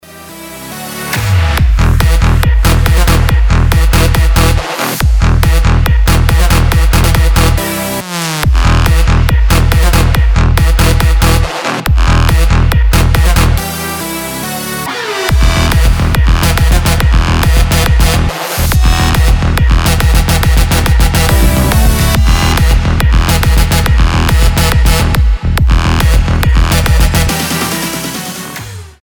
• Качество: 320, Stereo
ритмичные
громкие
EDM
без слов
Стиль: electro house, Melbourne bounce